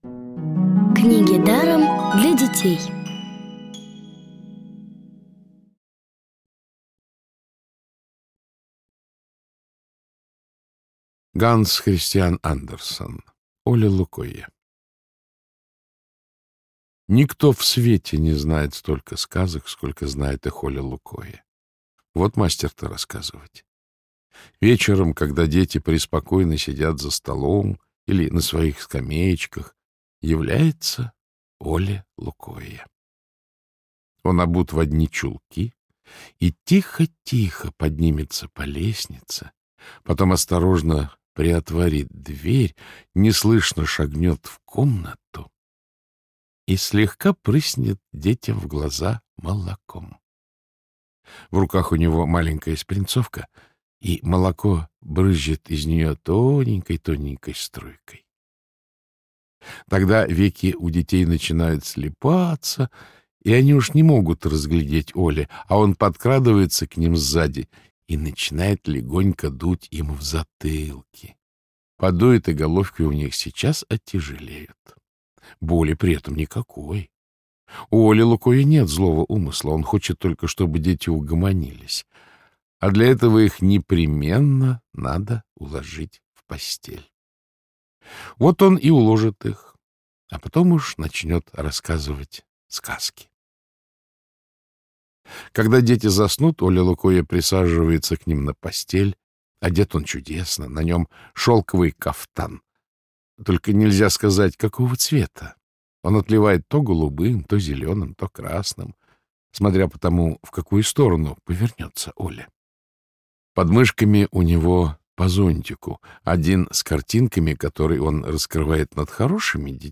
Аудиокнига бесплатно «Оле-Лукойе» от Рексквер.
Озвучивает А. КЛЮКВИН
Аудиокниги онлайн – слушайте «Оле-Лукойе» в профессиональной озвучке и с качественным звуком. Ханс Кристиан Андерсен - Оле-Лукойе.